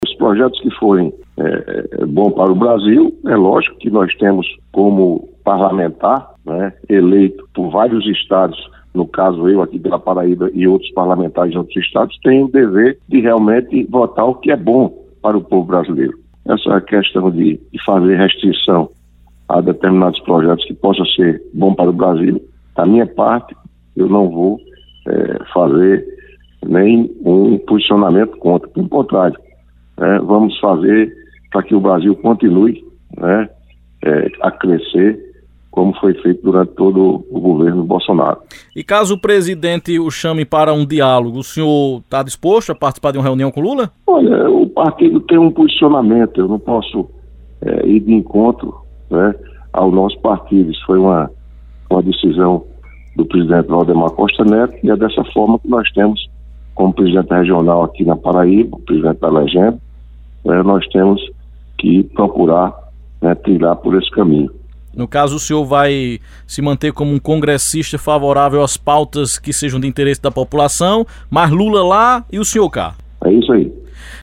Mesmo já tendo sido aliado do presidente Luís Inácio Lula da Silva no passado e participado de movimentos em defesa da liberdade do petista, o presidente do PL na Paraíba, deputado Wellington Roberto, negou qualquer aproximação com Lula, na tarde dessa segunda-feira (23/01), em entrevista ao Correio Debate.